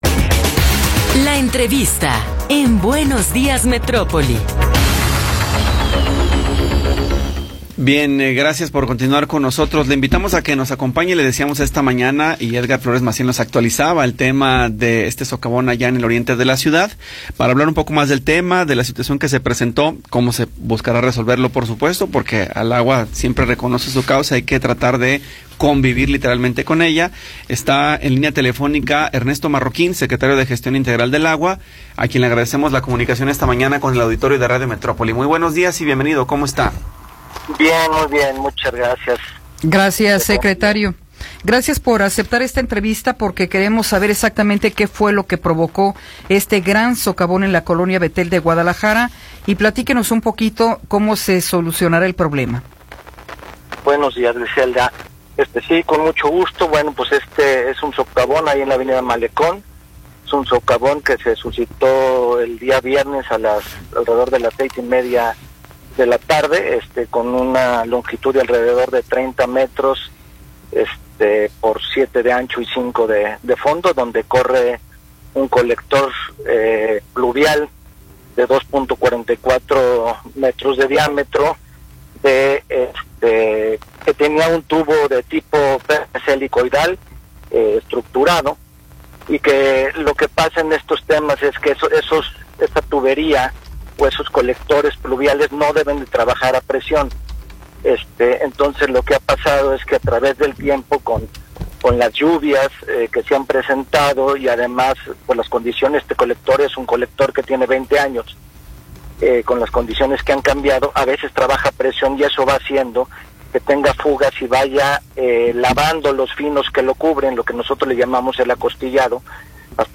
Entrevista con Ernesto Marroquín Álvarez